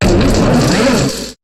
Cri d'Ohmassacre dans Pokémon HOME.